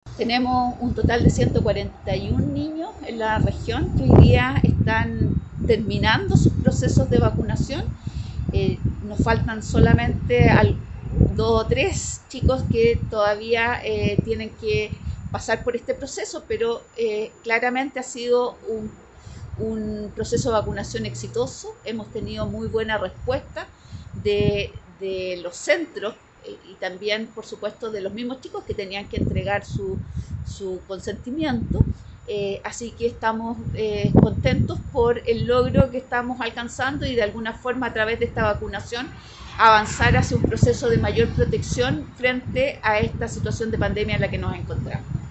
La directora regional del Servicio Nacional de Menores, Lilian Peña, señaló que en la región por tratarse de un acto voluntario, todas y todos los adolescentes priorizados para ser vacunados, debieron firmar un consentimiento informado y, además, sus adultos responsables debieron firmar un asentimiento.